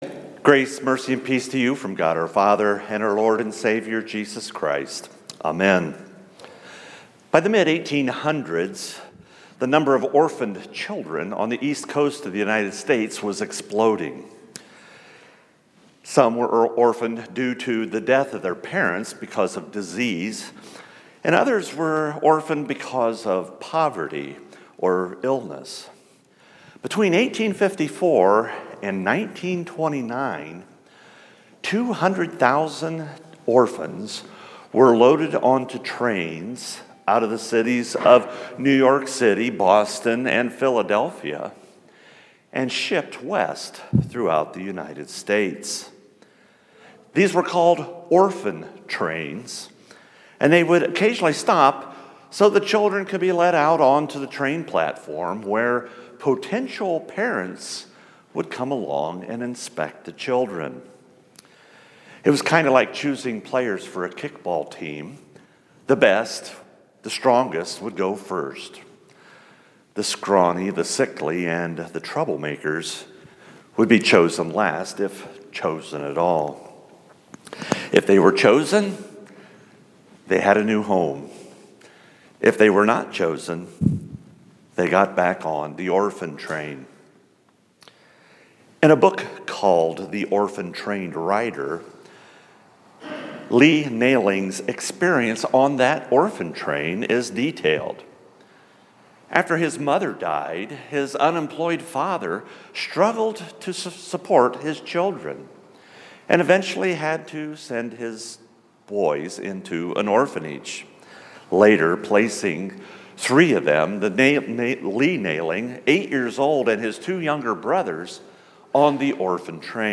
2017 Sermon Recordings